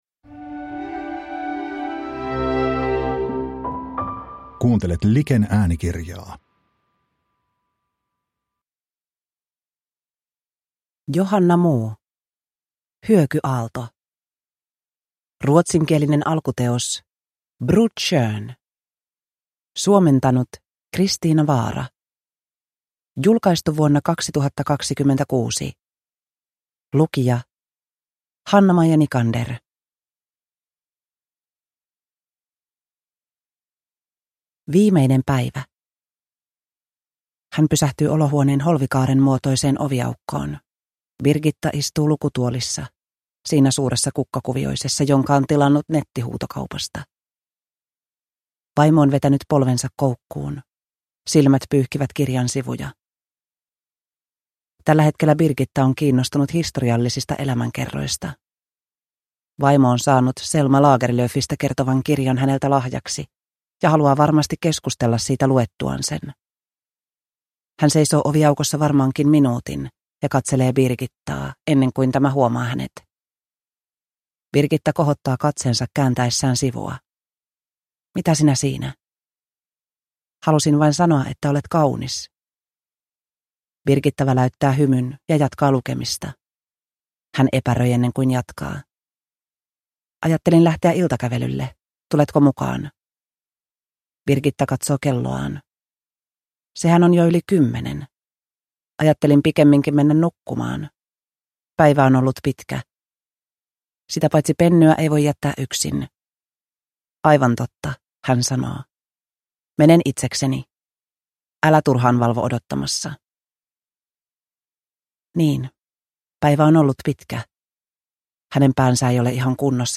Hyökyaalto – Ljudbok